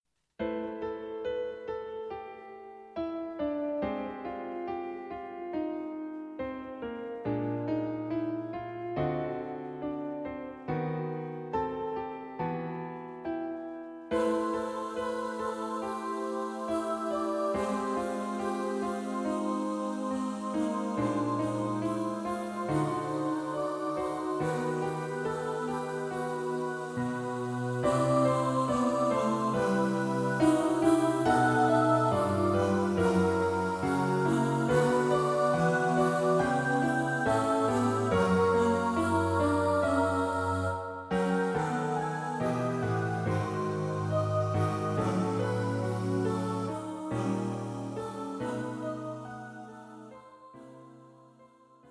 Choir (SATB) and Piano.
Thoughtful but, hopefully, not depressing.